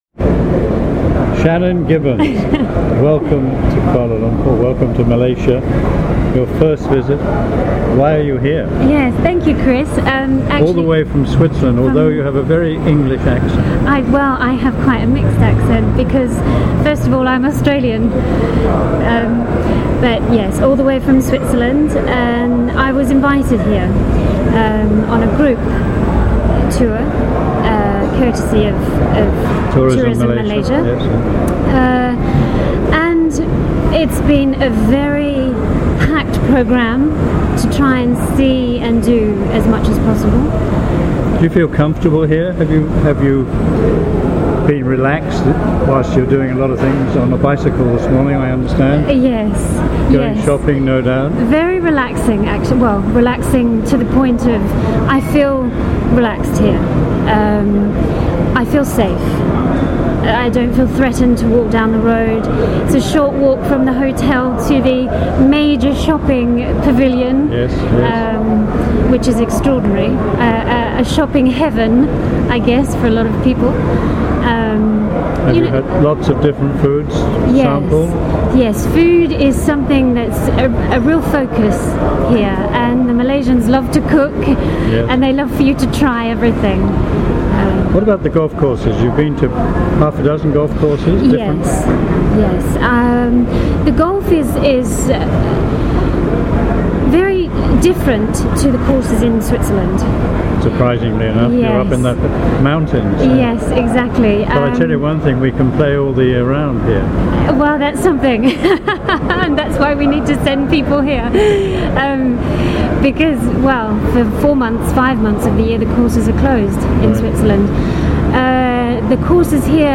MGTA interviews